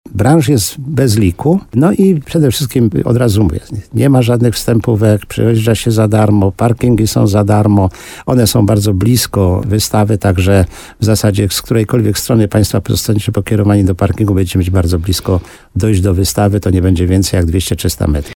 W programie Słowo za Słowo na antenie radia RDN Nowy Sącz
Rozmowa